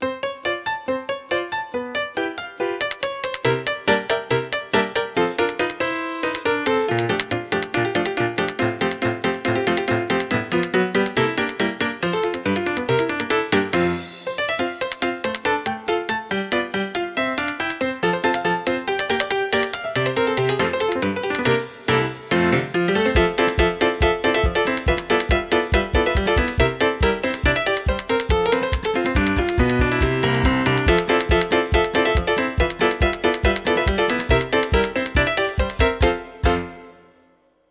piano
cómico
melodía
repetitivo
sintetizador